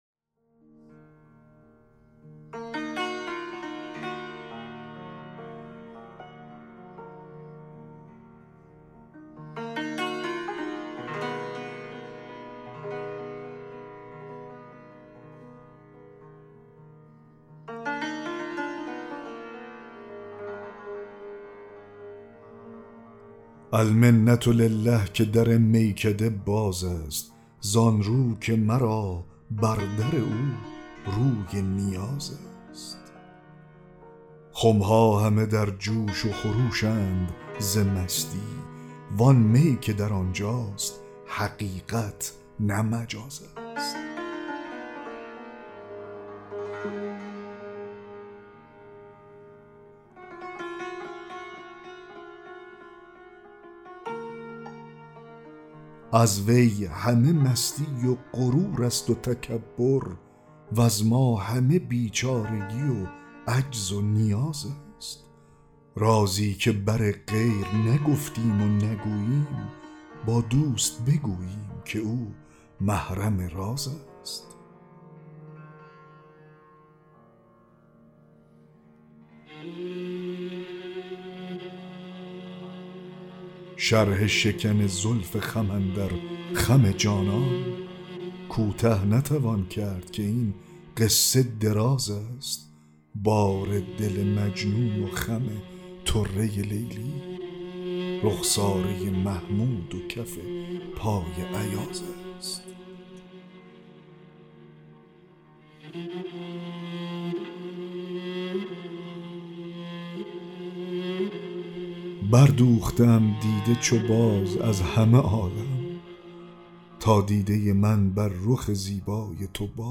دکلمه غزل 40 حافظ
دکلمه-غزل-40-حافظ-المنه-لله-که-در-میکده-باز-است.mp3